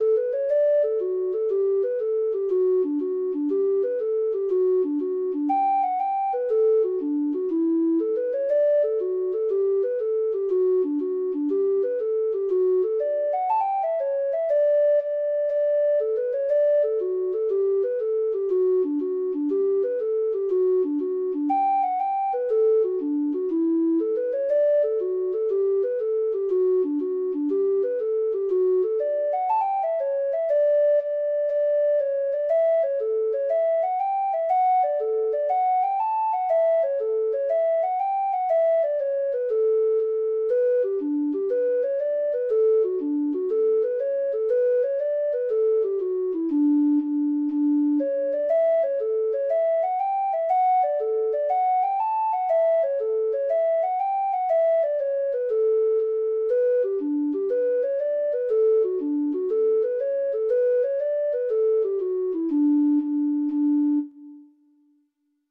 Hornpipes